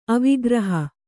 ♪ avigraha